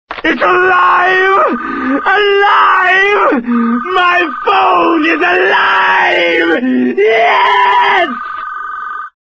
• Funny Ringtones